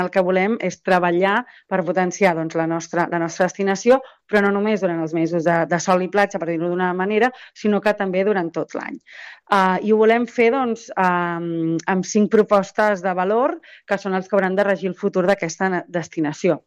En una entrevista al programa ONA MARESME de Ràdio Calella TV, la tinent d’Alcaldia de Turisme, Cindy Rando, ha parlat de la importància d’enfortir el model turístic de sol i platja però també el que atrau visitants al llarg de l’any i contribueixen a la desestacionalització.